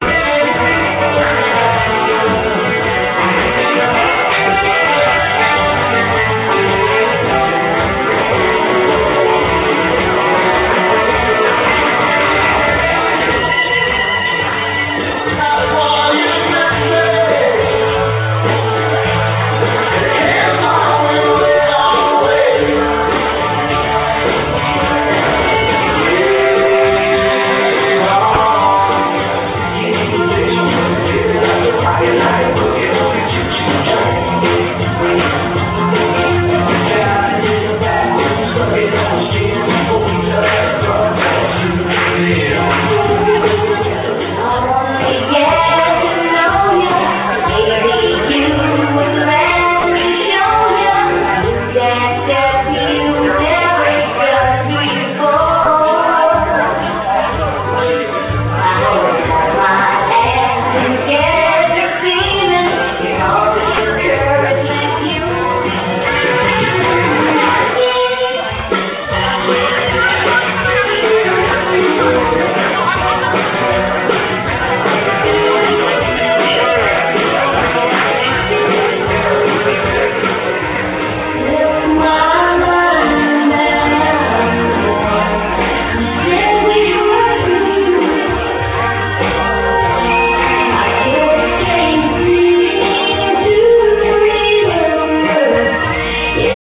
Country Night - Line and Partner Dancing